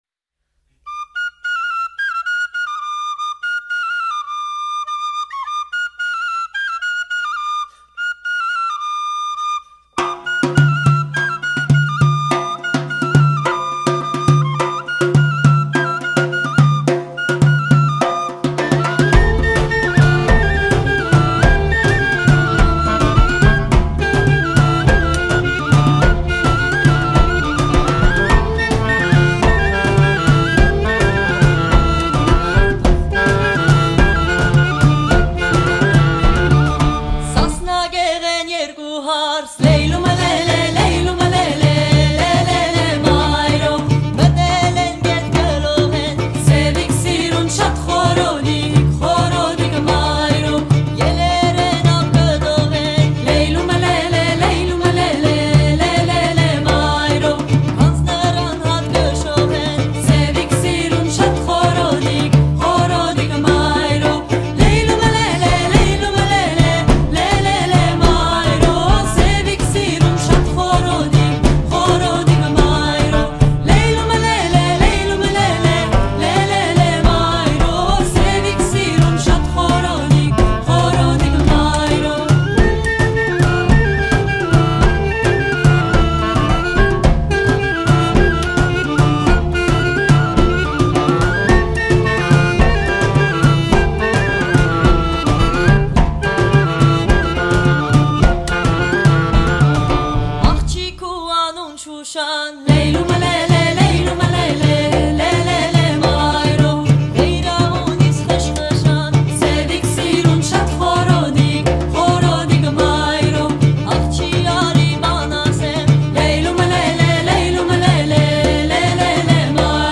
French ethno folk band
Ethno folk; Armenian; Turkish; Kurdish; French